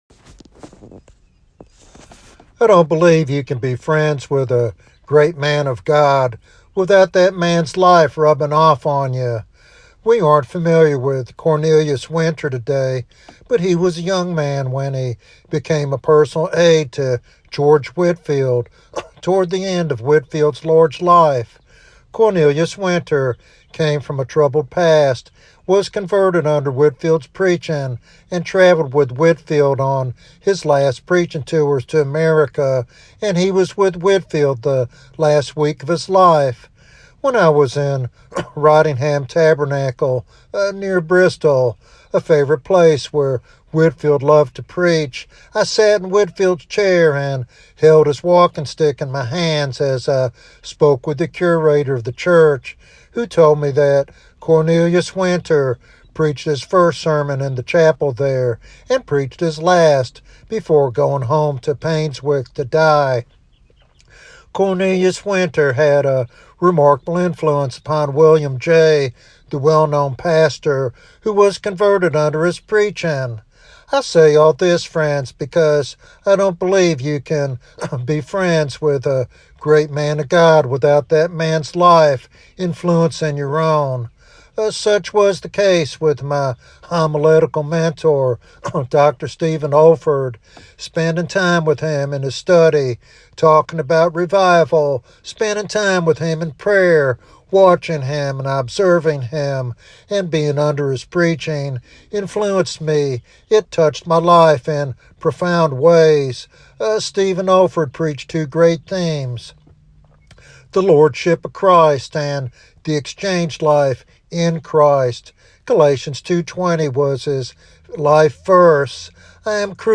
In this devotional sermon